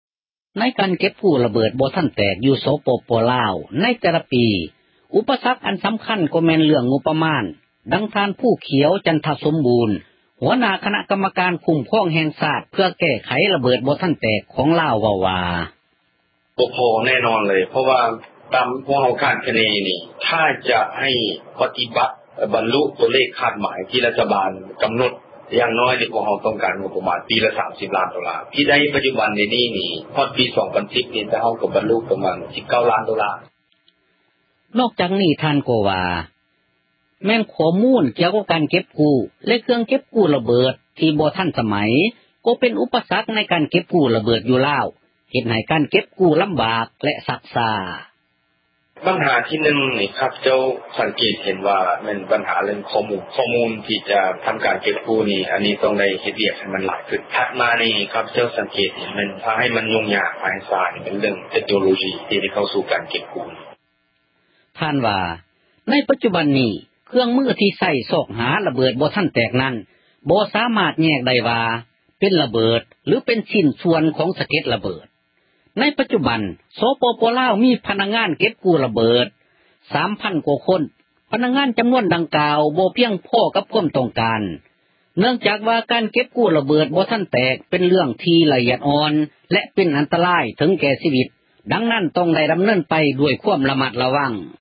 ທ່ານ ພູຂຽວ ຈັນທະສົມບູນ ຫົວໜ້າ ຄນະກັມມະການ ຄຸ້ມຄອງ ແຫ່ງຊາດ ເພື່ອແກ້ໄຂ ຣະເບີດ ບໍ່ທັນແຕກ ຂອງລາວ ເວົ້າວ່າ: